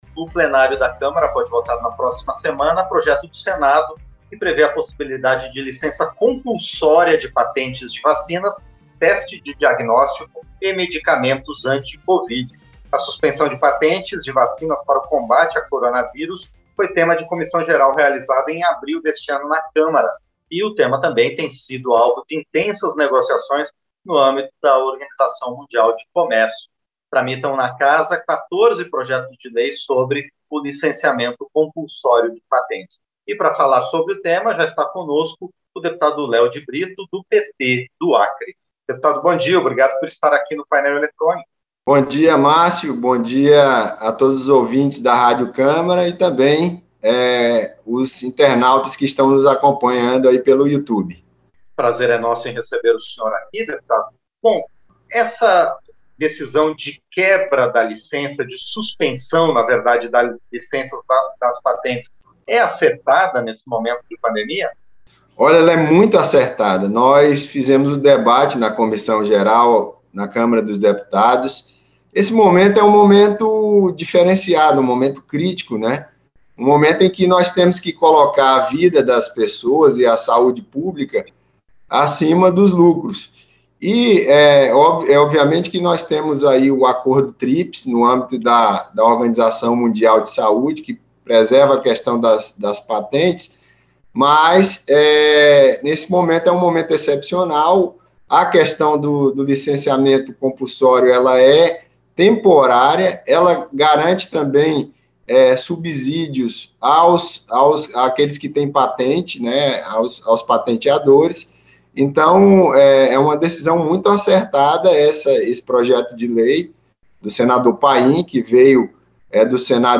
Entrevista - Léo de Brito (PT-AC)